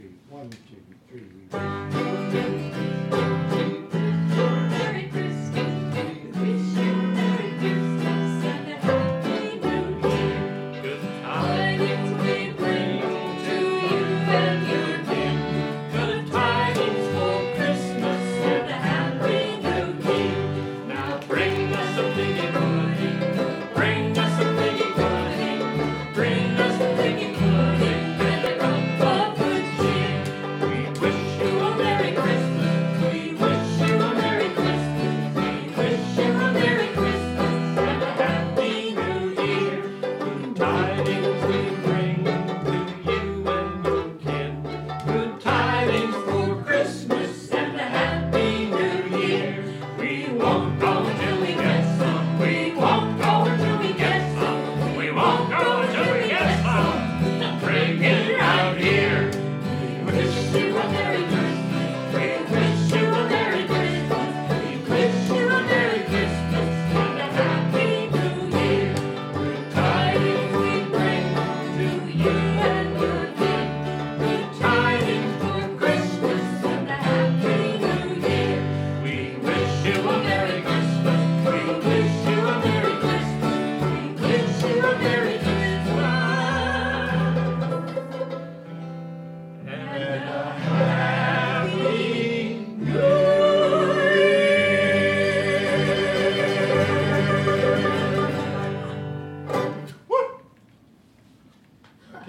Practice track